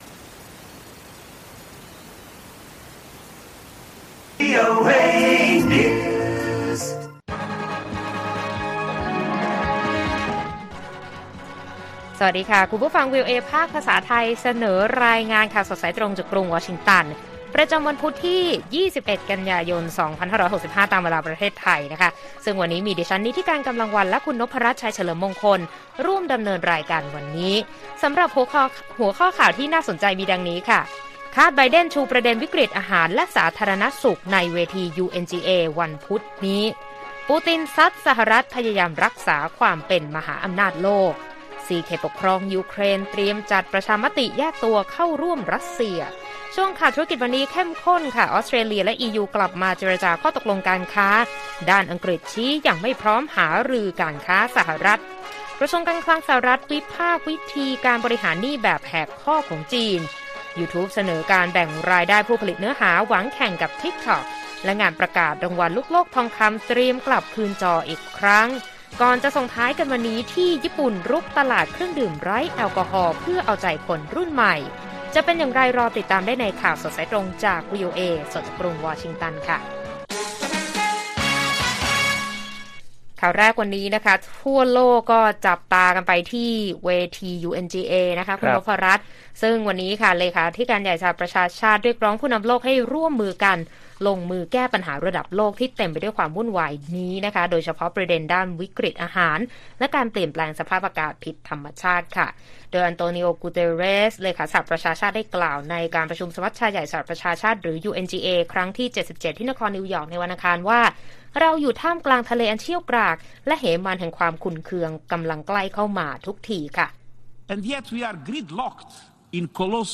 ข่าวสดสายตรงจากวีโอเอไทย 6:30 – 7:00 น. วันที่ 21 ก.ย. 65